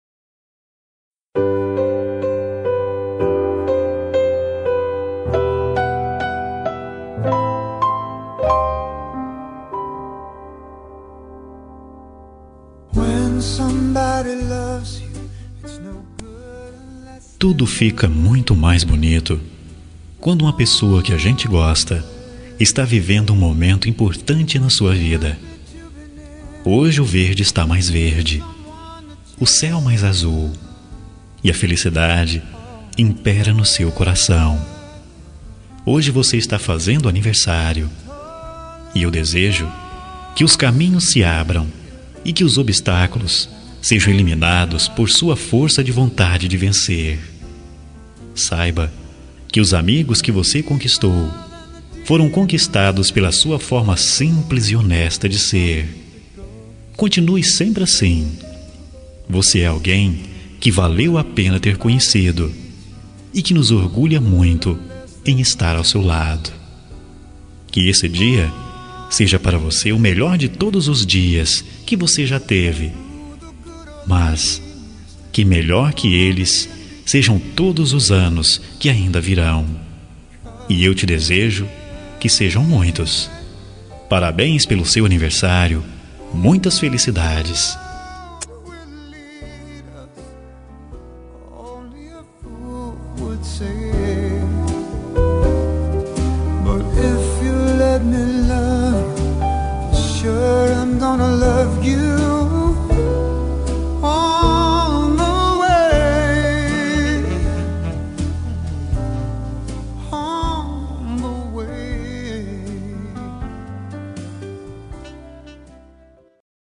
Aniversário de Pessoa Especial – Voz Masculina – Cód: 1915